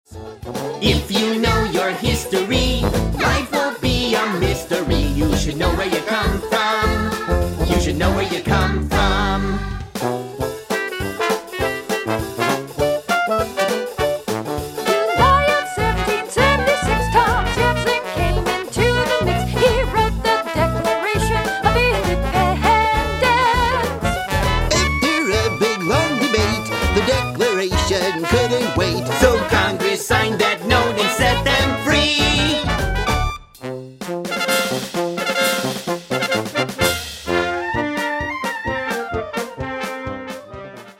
Children's Musical: